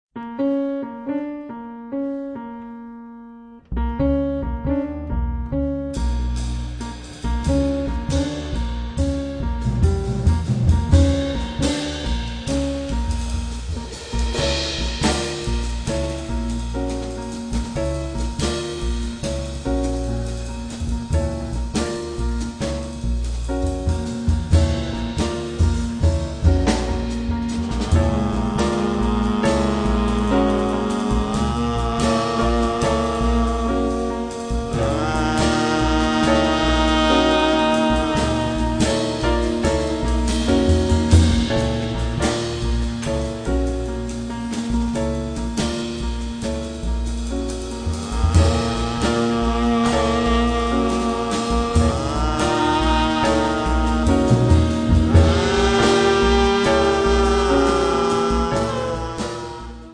sax tenore
pianoforte
contrabbasso
batteria